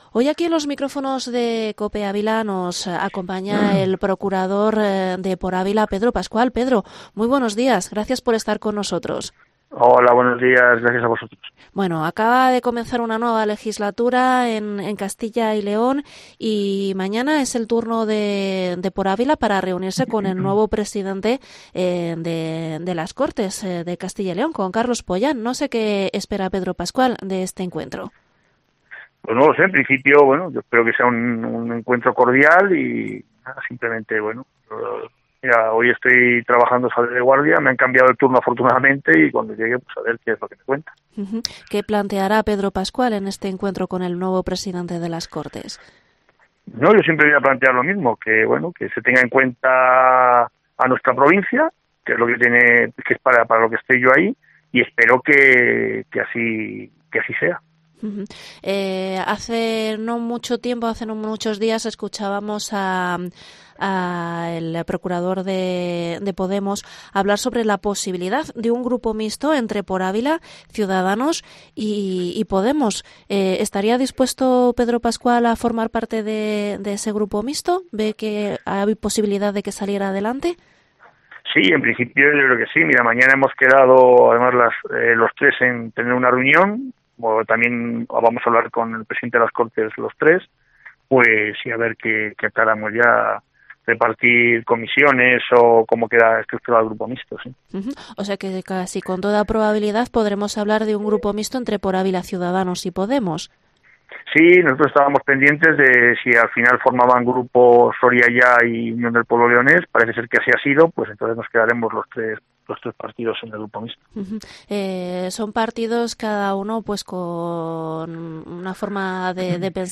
Entrevista Pedro Pascual, procurador de Por Ávila en las Cortes de Castilla y León